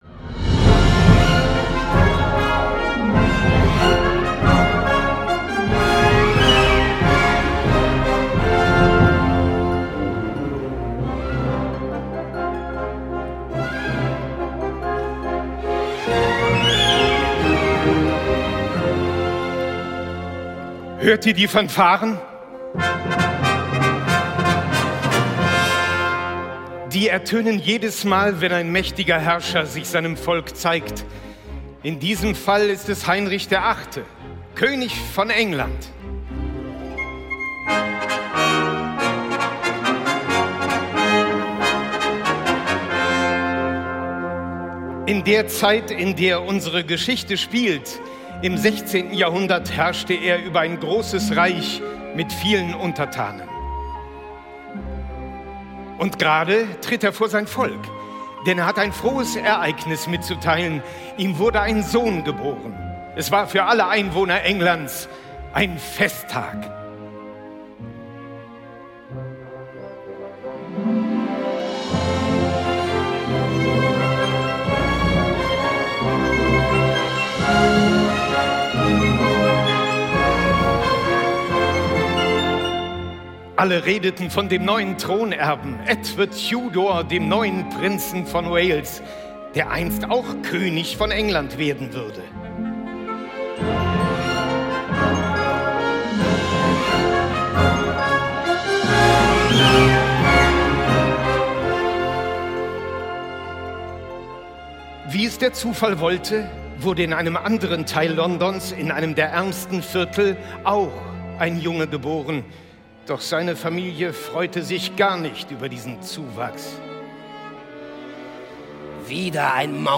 Orchesterhörspiel für Kinder von Henrik Albrecht.